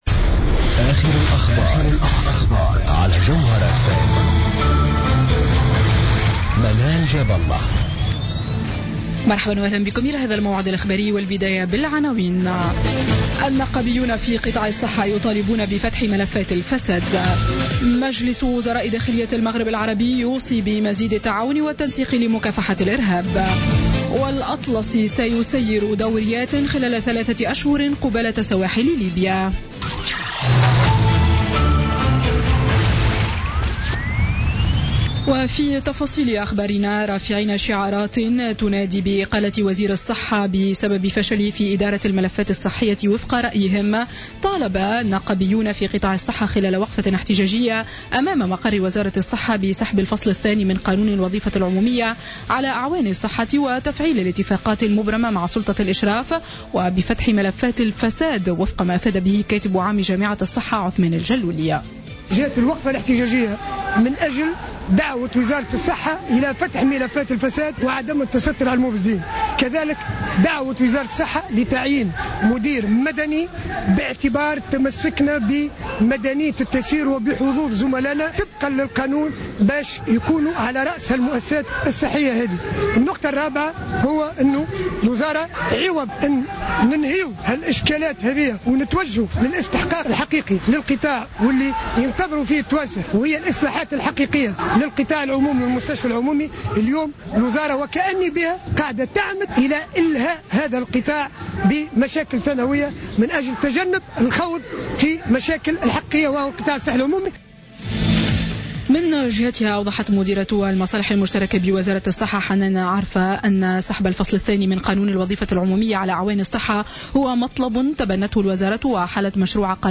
نشرة أخبار السابعة مساء ليوم الاثنين 25 أفريل 2016